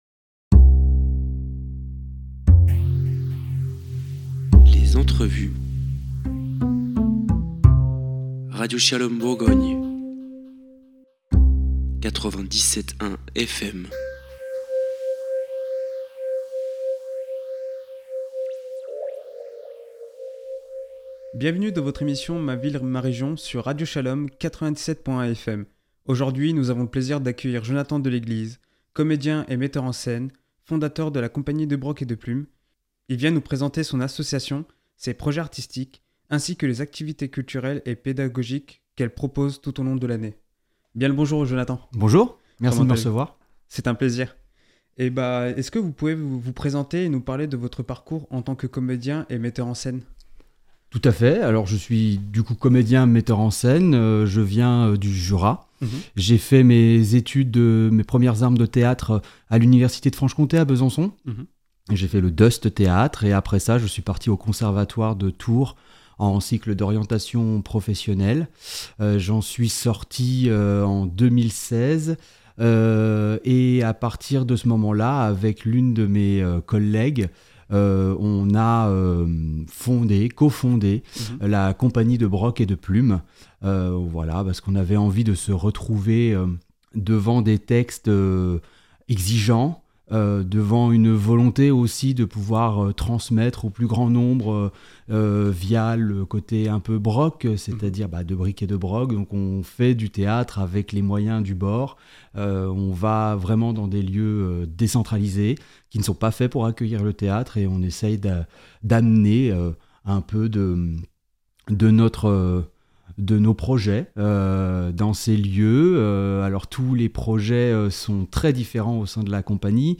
Animation